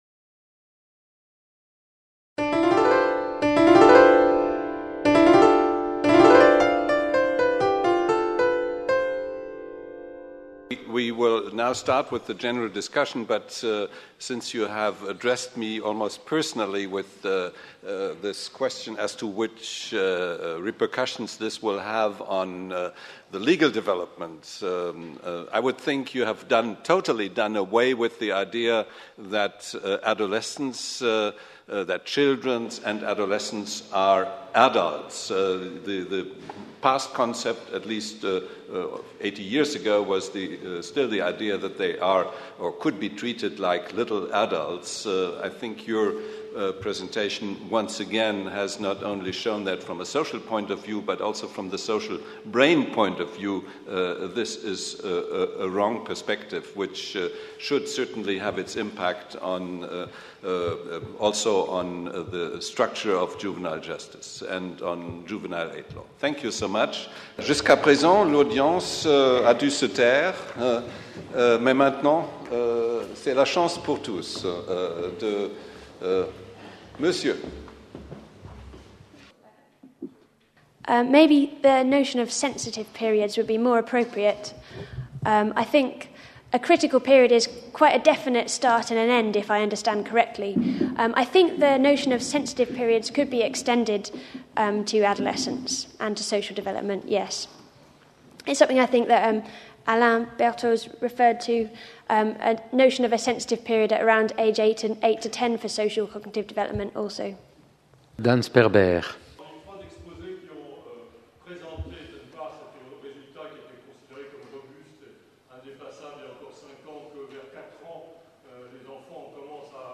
Colloque la pluralité interprétative - discussion générale | Canal U